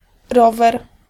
Ääntäminen
IPA: [bi.si.klɛt]